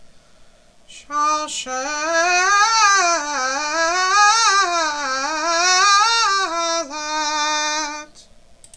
RARE TROPES
Shalshelet is sung four times in the Torah; in Parshat Vayera, Parshat Chayei Sara, Parshat Vayeshev and Parshat Tzav.